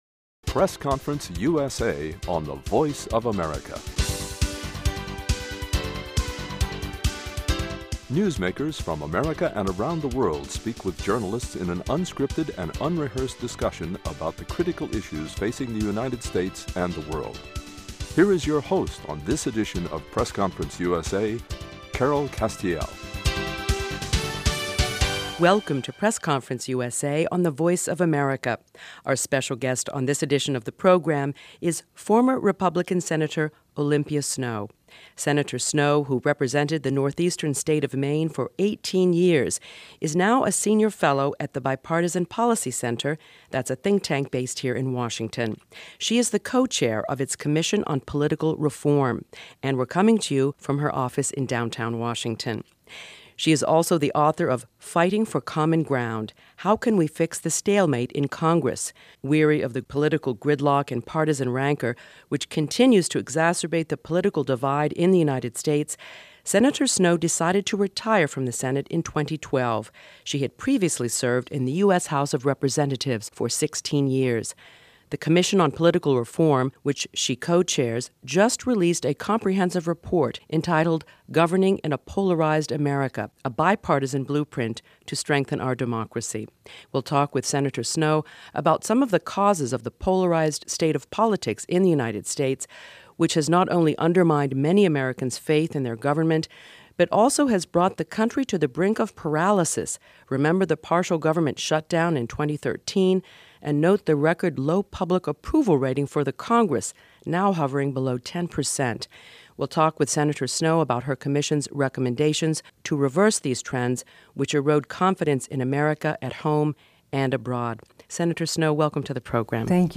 OLYMPIA SNOWE, FORMER US SENATOR, MAINE, CURRENT SENIOR FELLOW, BIPARTISAN POLICY CENTER